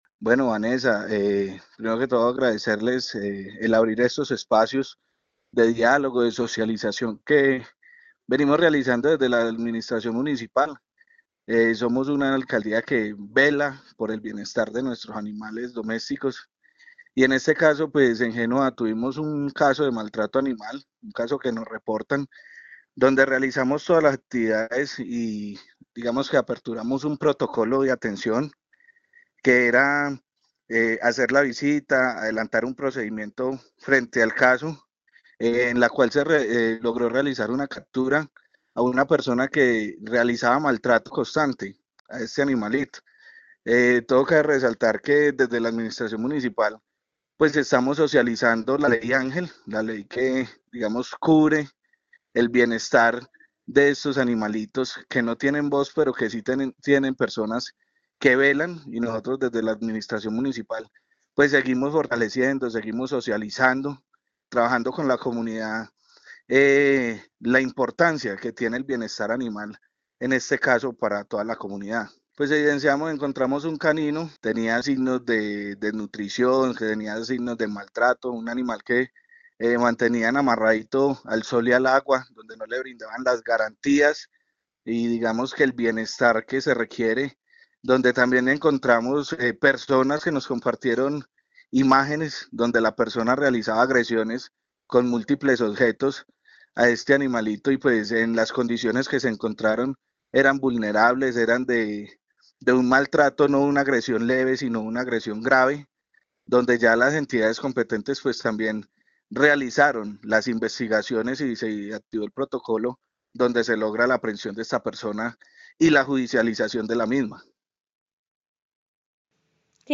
Secretario de Agricultura de Génova